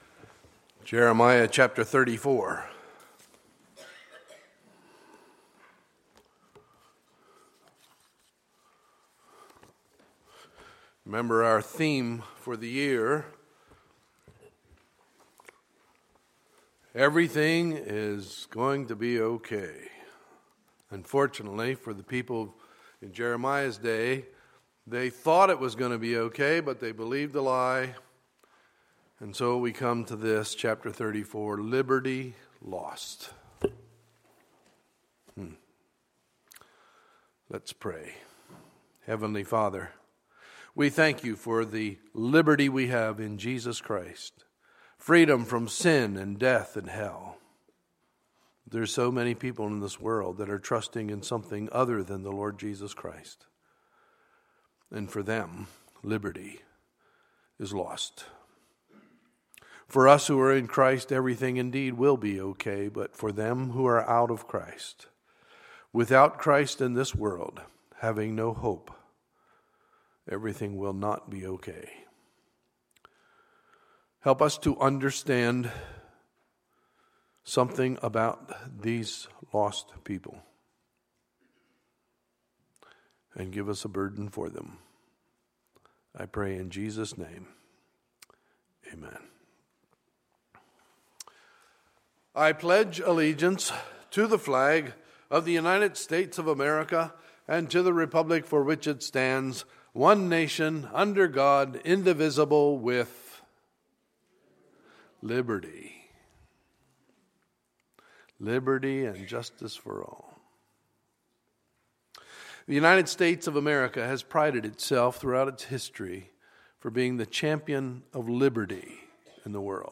Sunday, October 25, 2015 – Sunday Morning Service